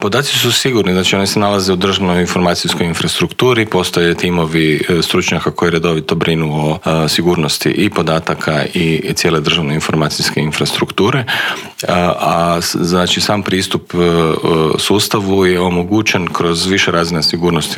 ZAGREB - U Intervjuu tjedna Media servisa gostovao je državni tajnik u Središnjem državnom uredu za razvoj digitalnog društva Bernard Gršić koji nam je otkrio kako je proteklo prvo online samopopisivanje građana, koliko ljudi koristi sustav e-Građani, što sve taj sustav nudi, što planira ponuditi kao i koje su njegove prednosti.